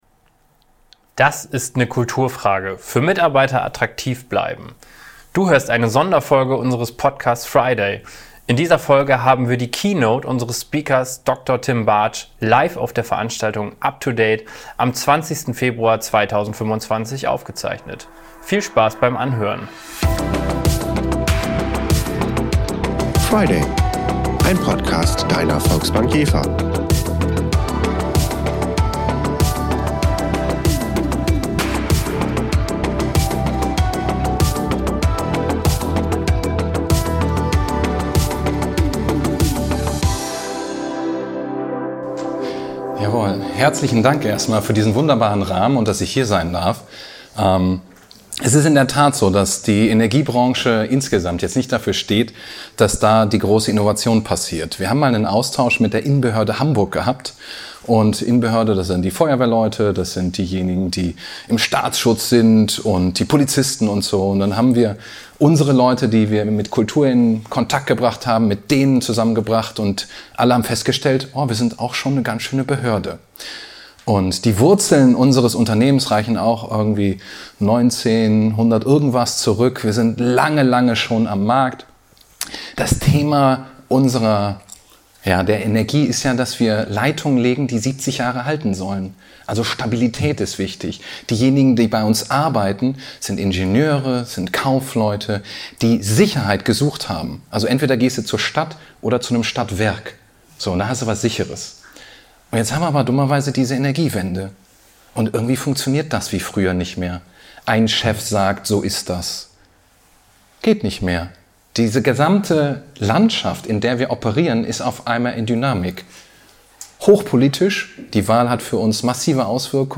Diese VRiday-Episode wurde im Rahmen der Up2Date-Veranstaltungsreihe live vor Publikum aufgezeichnet.